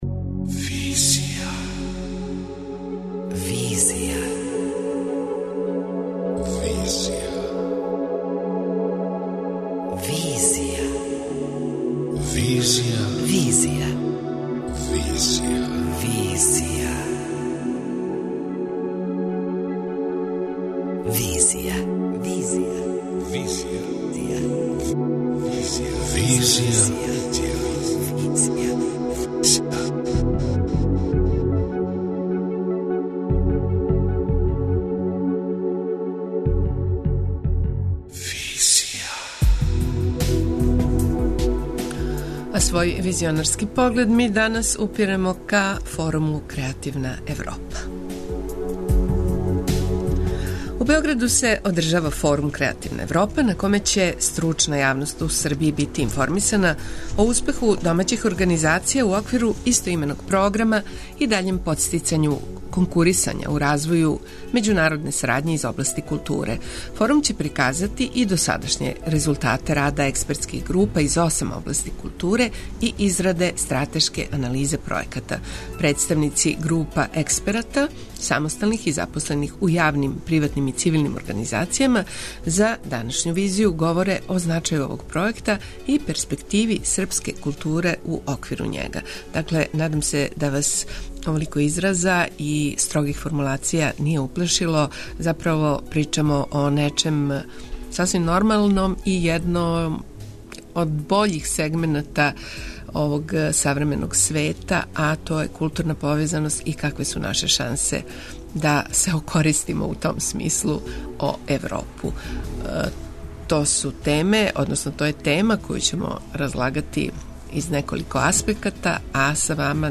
преузми : 27.23 MB Визија Autor: Београд 202 Социо-културолошки магазин, који прати савремене друштвене феномене.